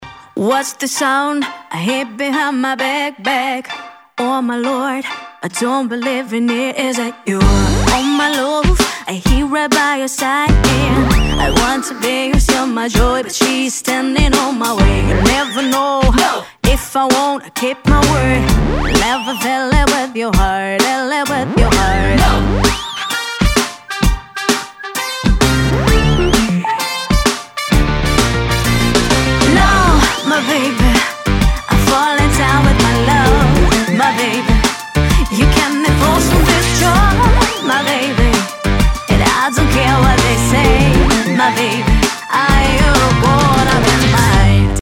• Качество: 192, Stereo
Заводная песня про детку из сериала